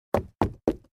SFX_Run.mp3